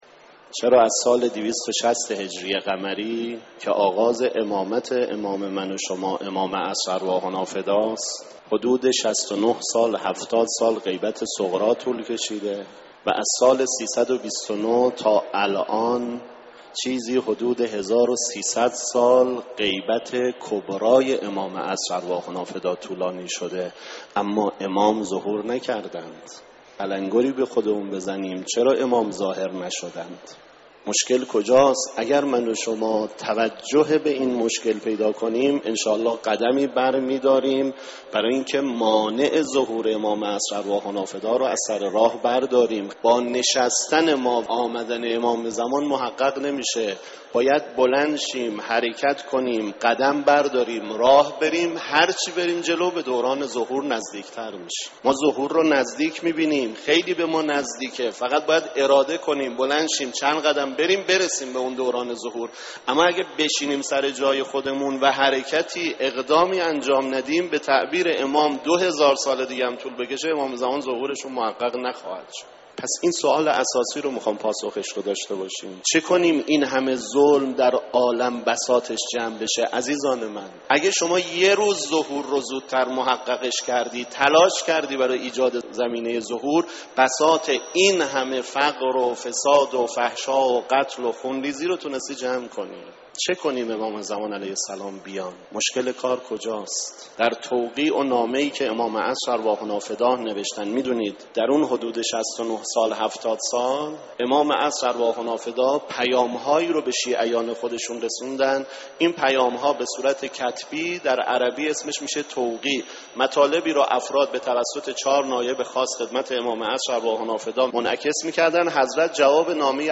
فرازی از سخنرانی